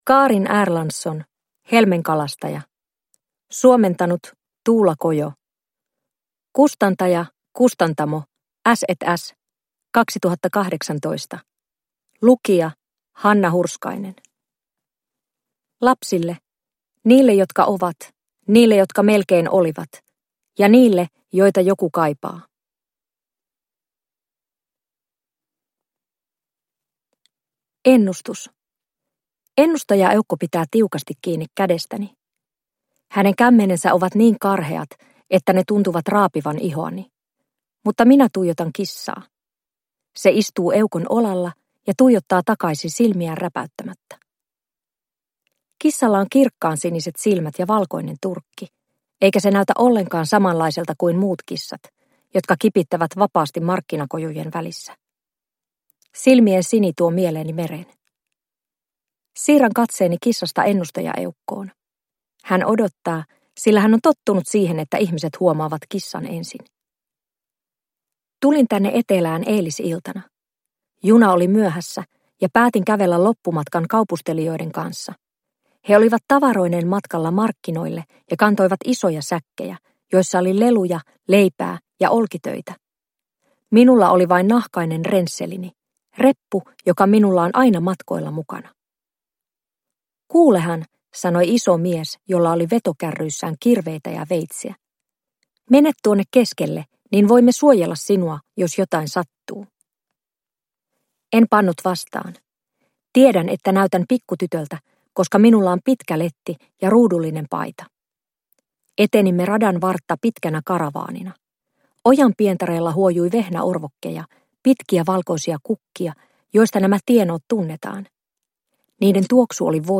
Helmenkalastaja – Ljudbok – Laddas ner